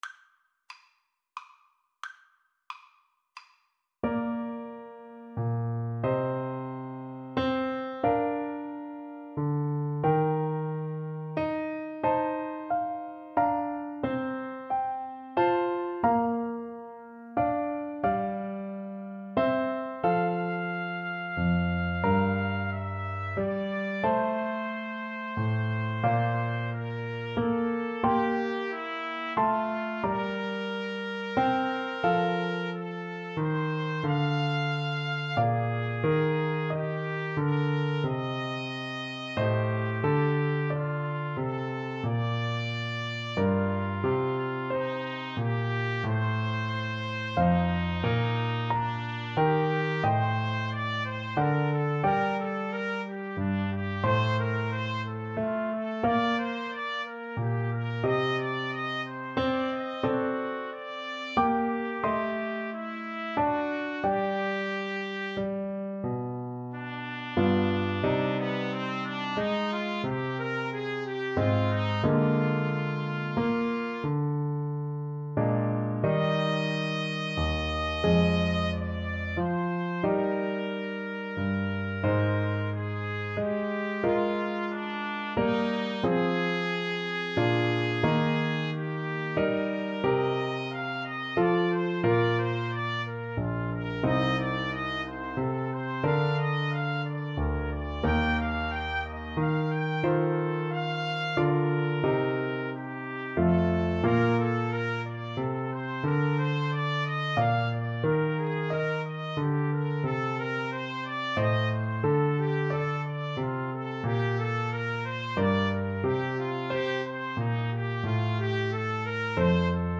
Largo ma non tanto ( = c. 90)
3/4 (View more 3/4 Music)